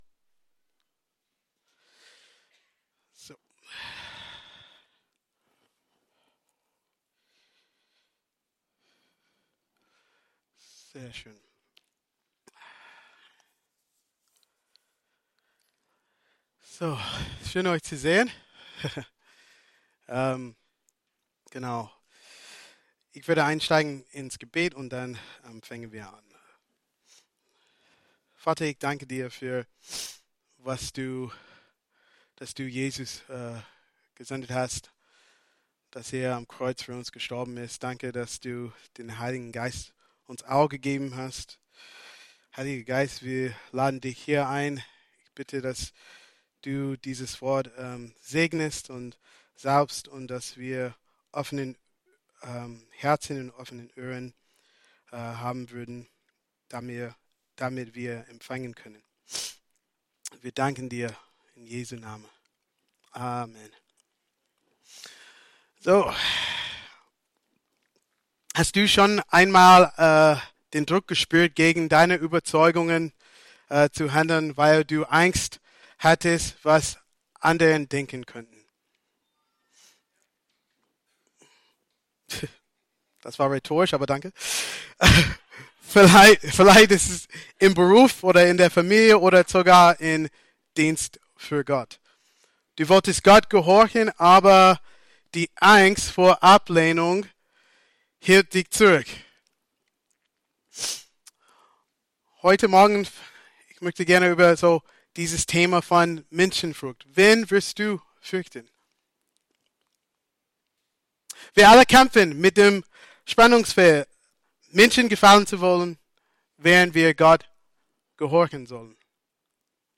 Predigt vom 06.04.2025